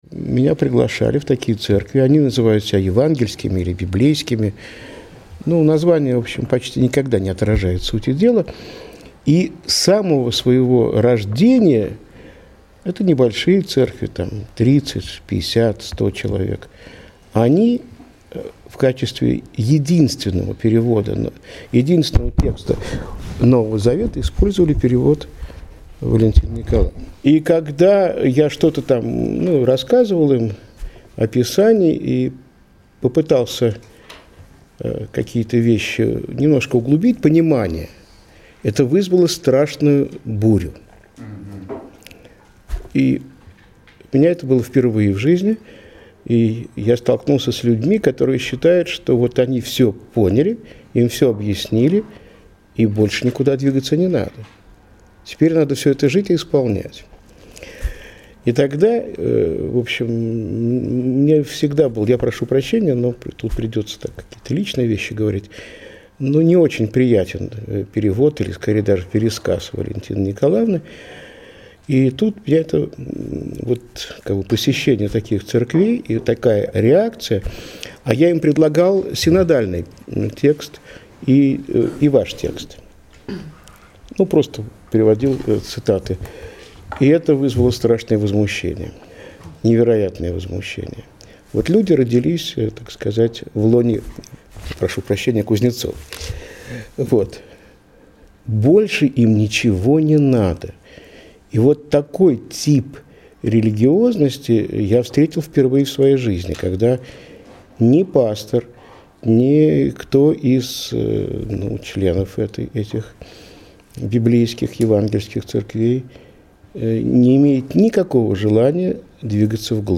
КОНФЕРЕНЦИЯ 2011
Фрагменты выступлений в формате mp3.